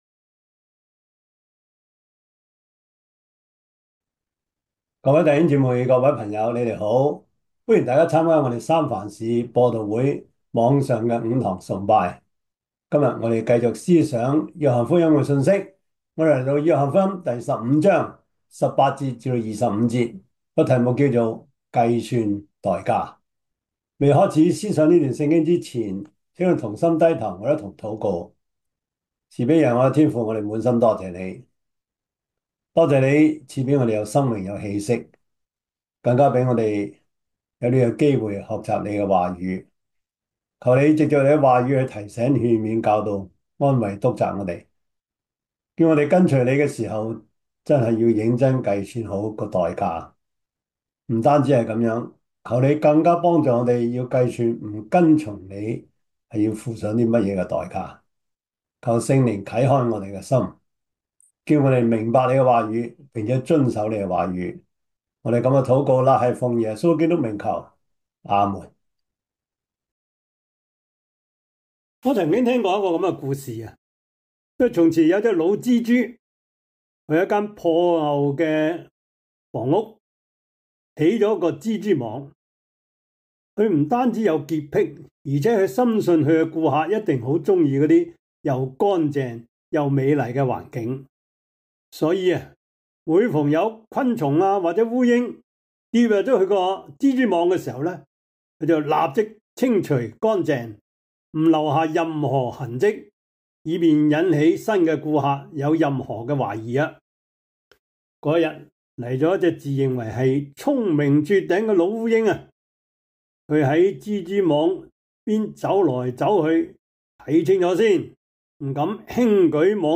約翰福音 15:18-25 Service Type: 主日崇拜 約翰福音 15:18-25 Chinese Union Version
Topics: 主日證道 « 信望愛-迎接2025 第八十課: 天國與政治 – 第十七講 什麼是政治?